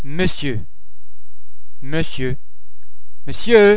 ·[ on ]